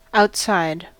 Ääntäminen
US : IPA : [ˌaʊt.ˈsaɪd]